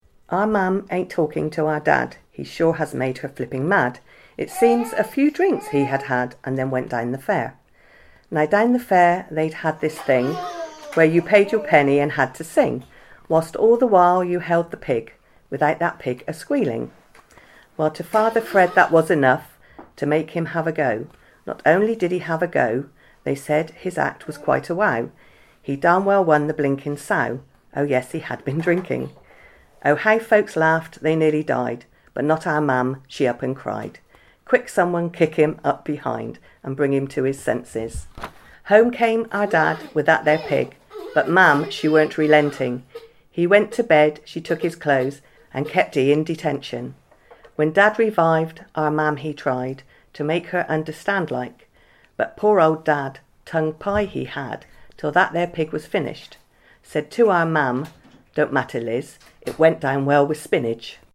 ABSOLUTE THEATRE in partnership with learners from the ISLE OF PORTLAND ALDRIDGE COMMUNITY ACADEMY, recorded these anecdotes, memories and experiences of Portland people.